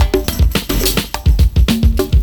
106PERCS03.wav